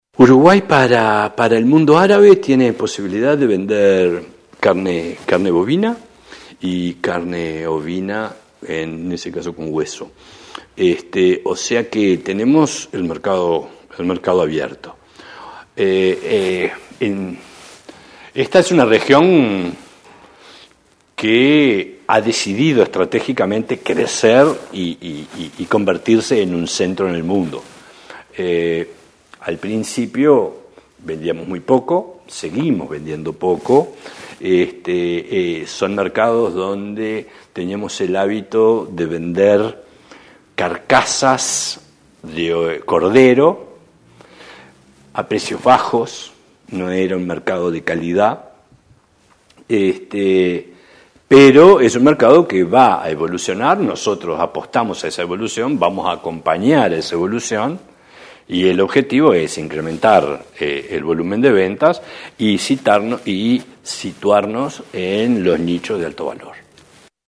Así lo expresó el presidente del Instituto Nacional de Carne, Gaston Scayola, quien recordó que la industria nacional cuenta con la habilitación para exportar carne bovina y ovina con hueso a ese mercado.